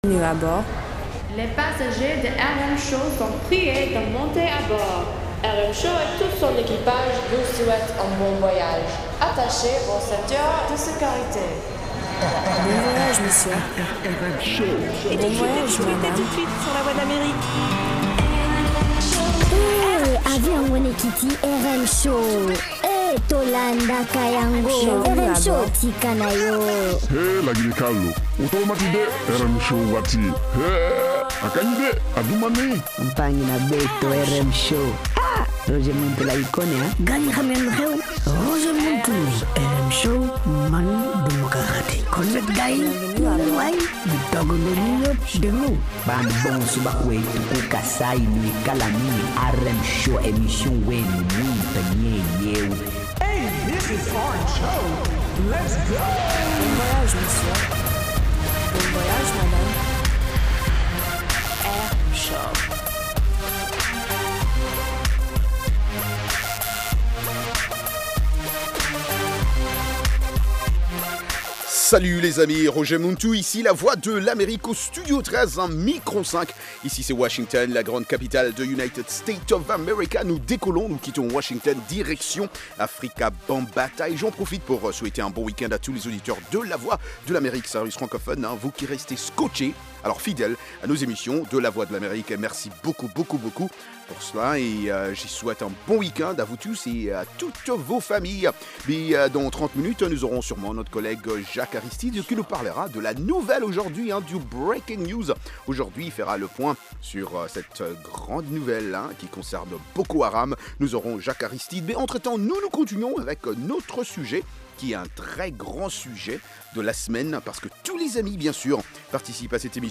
Zouk, Reggae, Latino, Soca, Compas et Afro